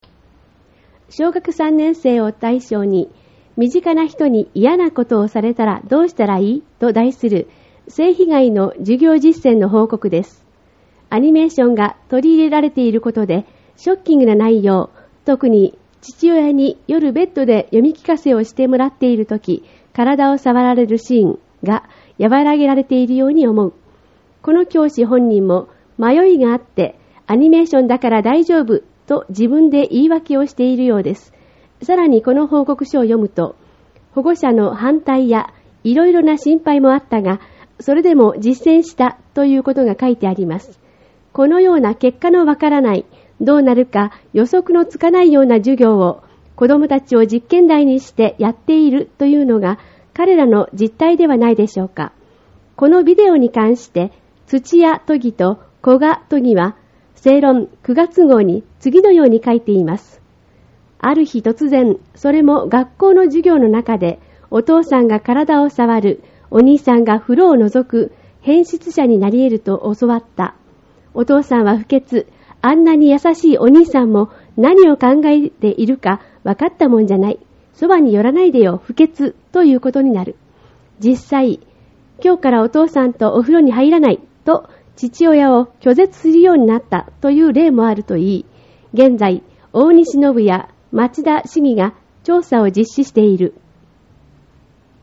音声による説明 　小学３年生を対象に、「身近な人にいやなことされたら、どうしたらいい？」と、題する性被害の授業実践の報告です。